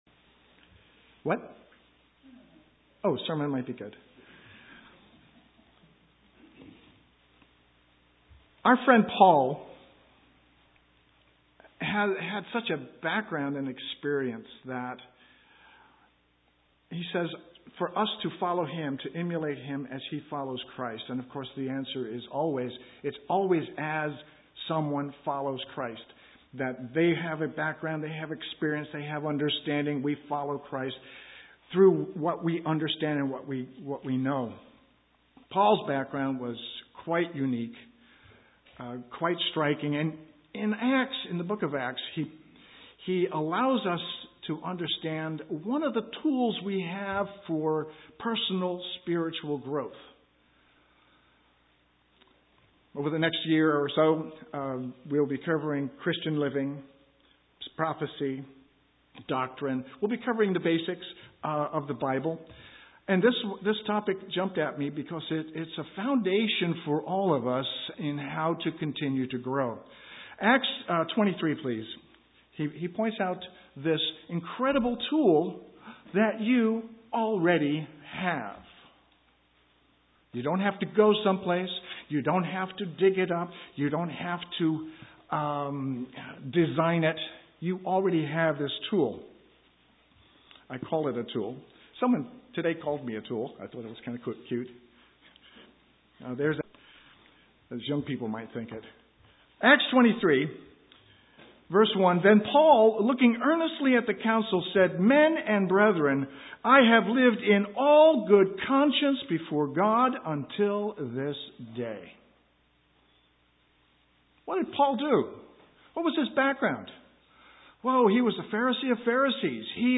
Given in Eureka, CA
UCG Sermon Studying the bible?